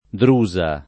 [ dr 2@ a ]